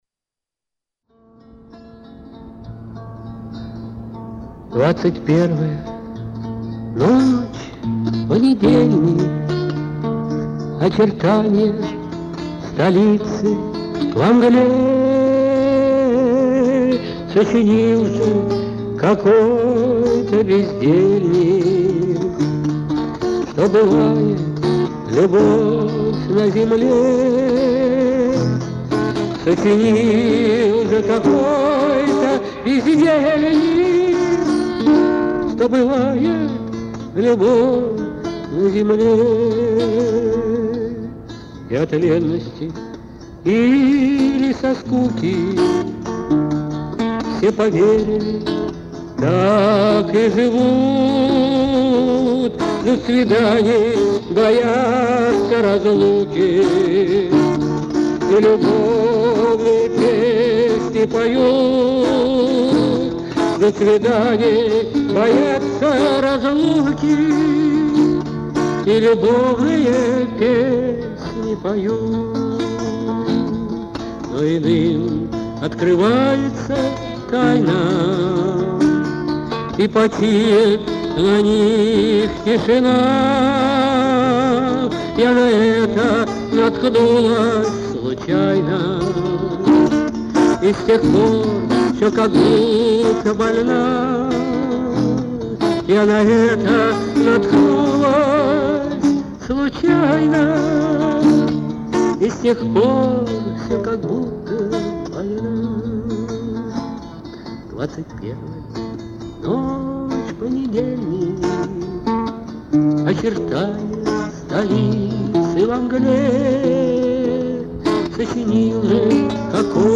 вокал, гитара
Романс